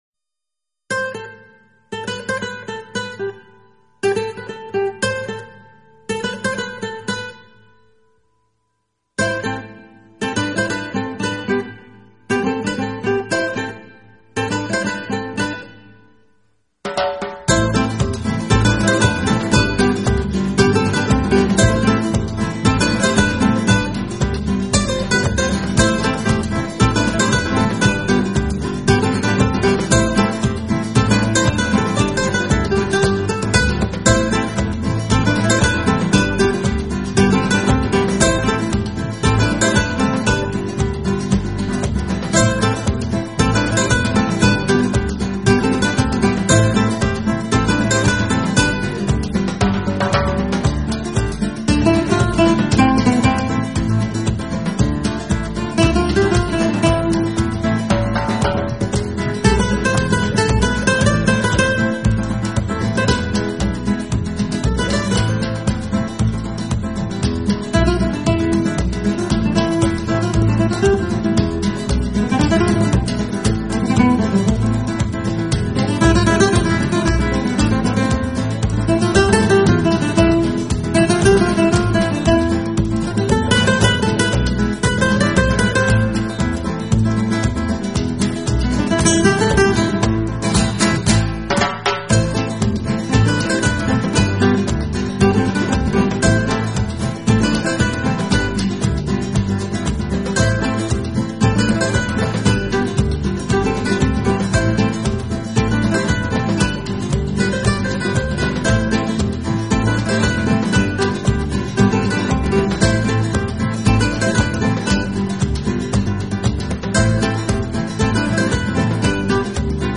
音乐类型：New Age
然流露出西班牙、德国及中国的传统音乐气息。